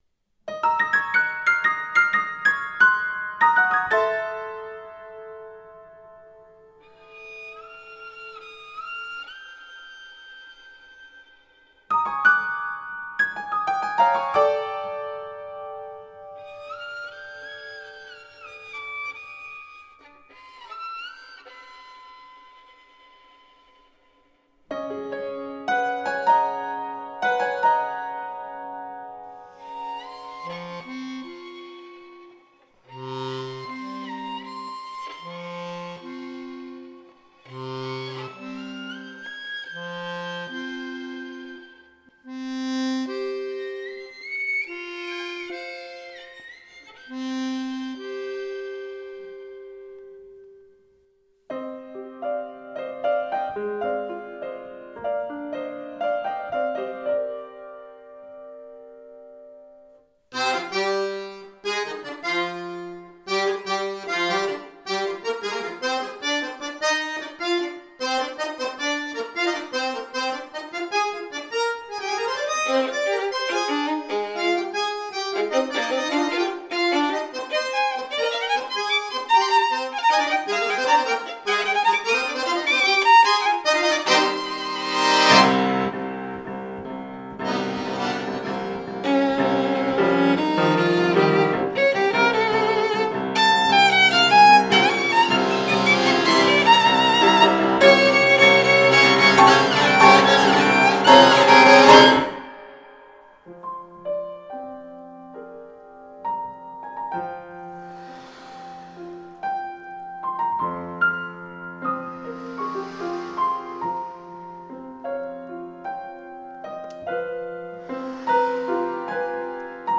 Violino, Fisarmonica e Pianoforte